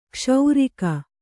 ♪ kṣaurika